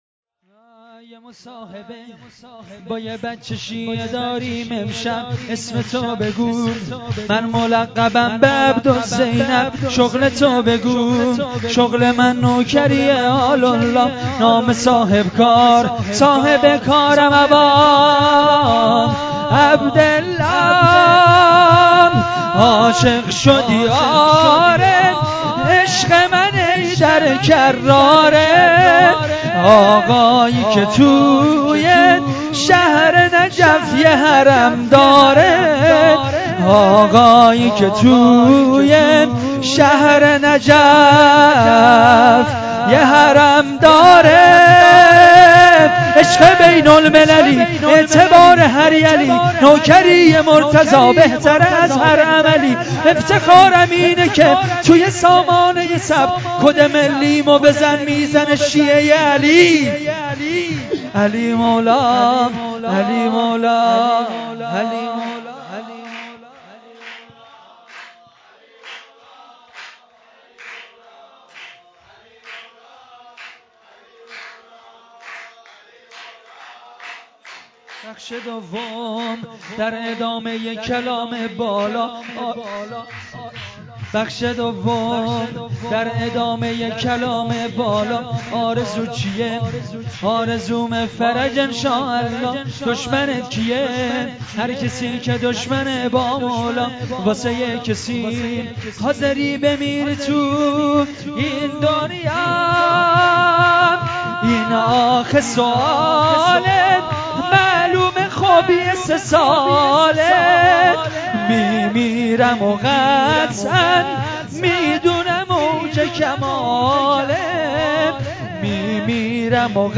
جشن بزرگ مبعث رسول مکرم اسلام1403